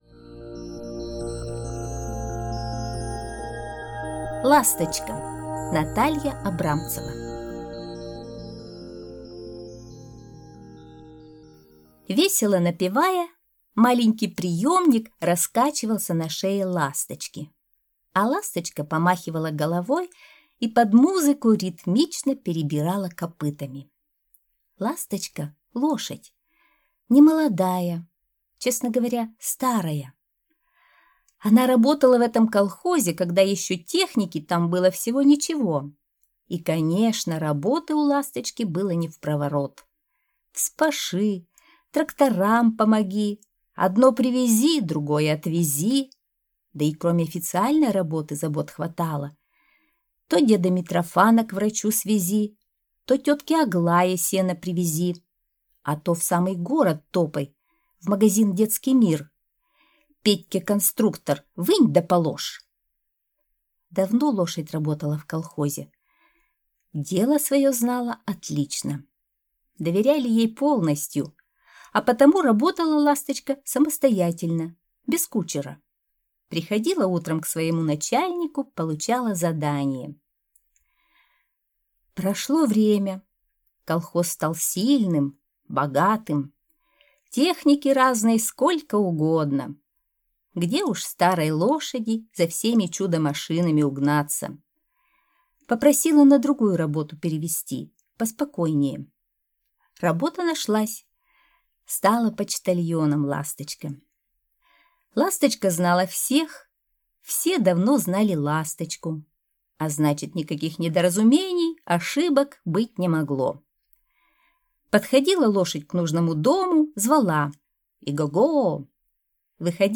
Ласточка - аудиосказка Натальи Абрамцевой - слушать скачать